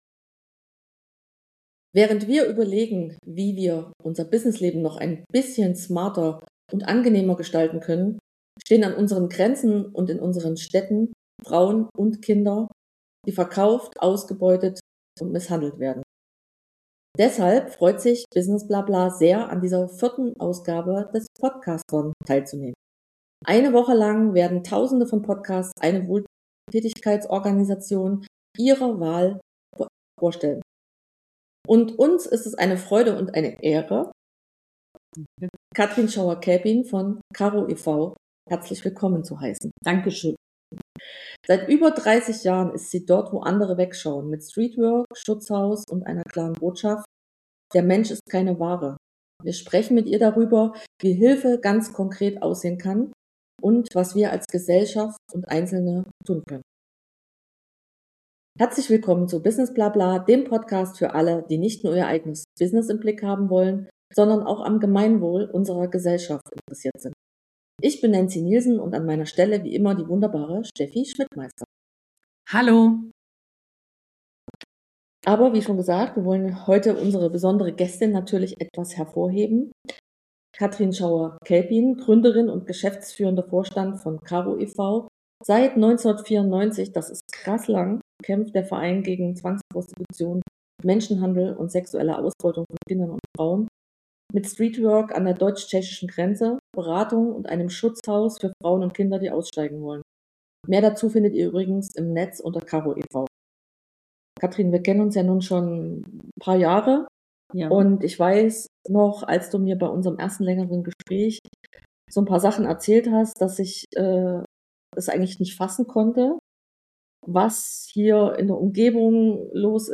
Hinschauen statt Wegsehen. Ein Gespräch mit Karo e.V. ~ Business Bla Bla Podcast